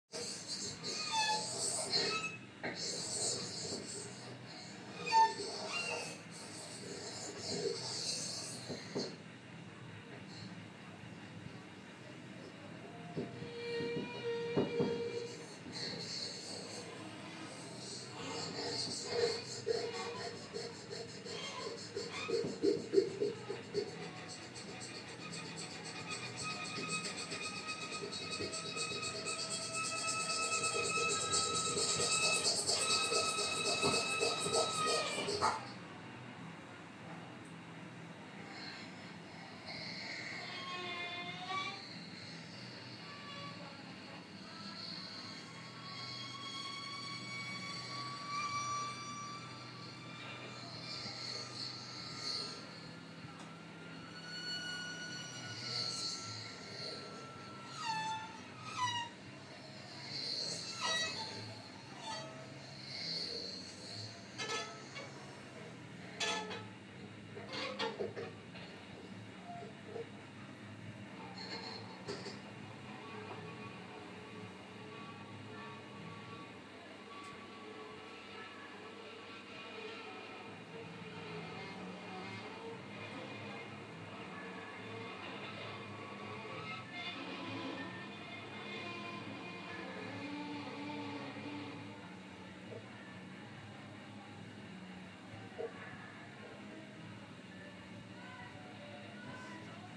Improvised music